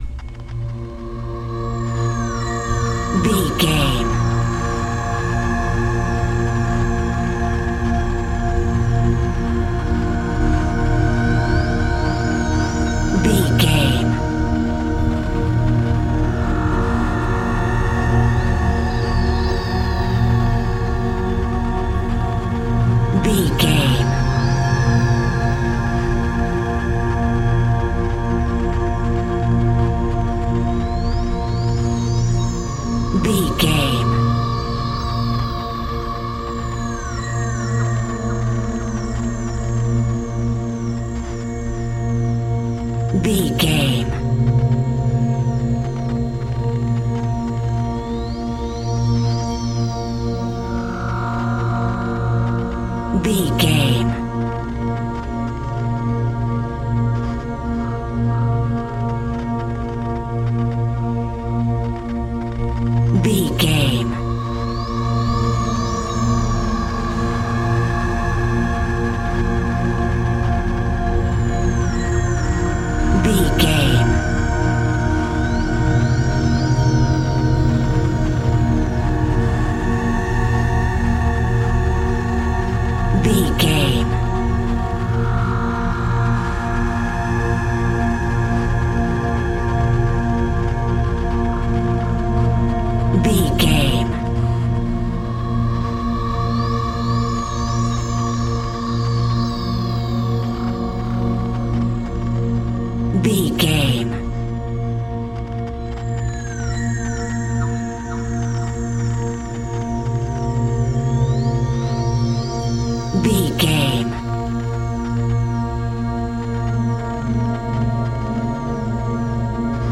Horror Scary Ambience Music.
Aeolian/Minor
B♭
Slow
ominous
dark
haunting
eerie
synthesiser
Synth Pads
atmospheres